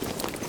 tac_gear_19.ogg